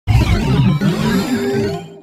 Grito de Blacephalon.ogg
Grito_de_Blacephalon.ogg.mp3